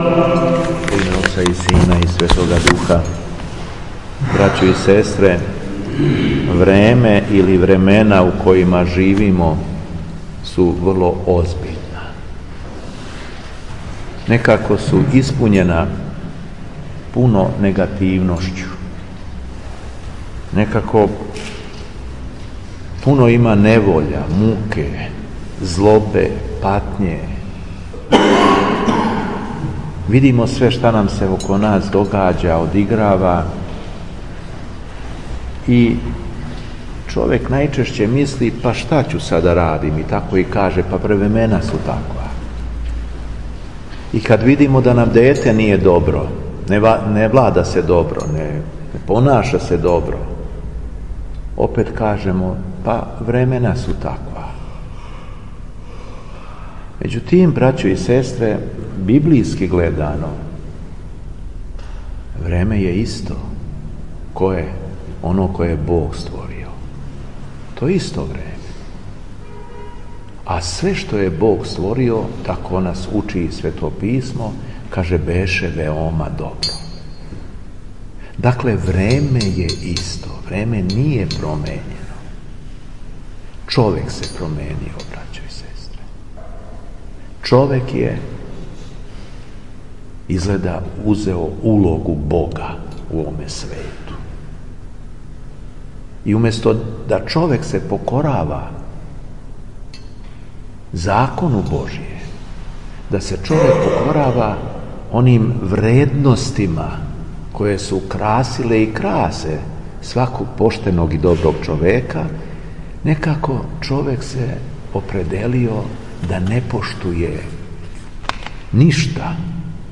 У петак, 7. децембра 2018. године, када наша Црква слави свету великомученицу Екатерину и светог великомученика Меркурија, Његово Преосвештенство Епископ шумадијски Господин Јован служио је свету Литургију у храму Свете Петке у крагујевачком насељу Виногради.
Беседа Епископа шумадијског Г. Јована